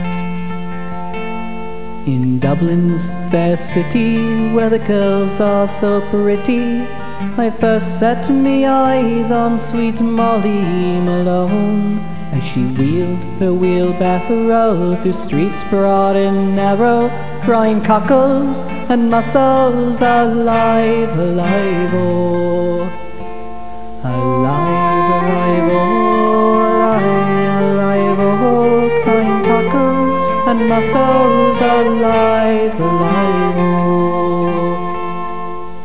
"Molly Malone" è una classica ballata popolare irlandese, ed è la canzone che Zagor, da bambino, sentiva cantare da sua madre.